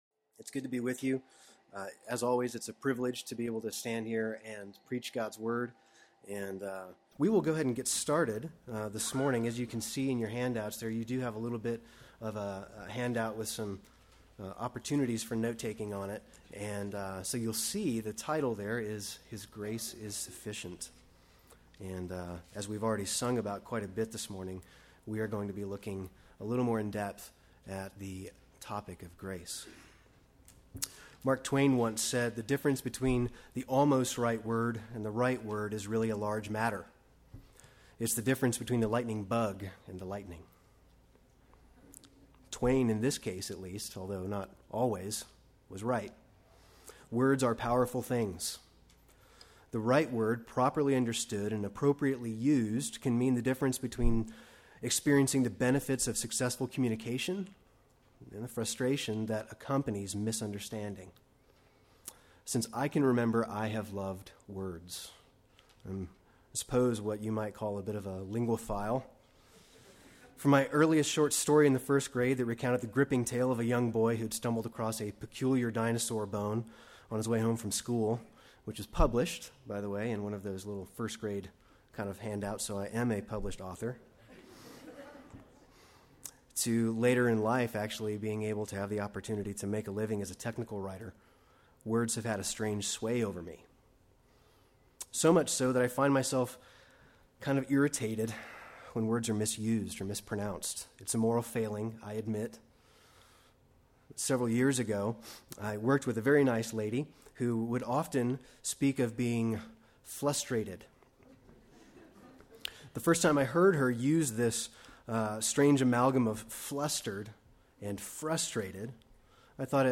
Play Sermon Get HCF Teaching Automatically.
His Grace Is Sufficient Sunday Worship